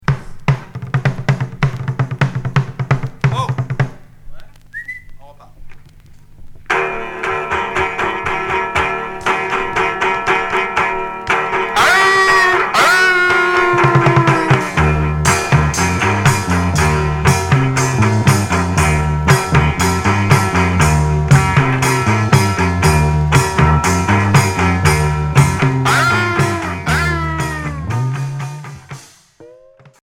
Garage trash core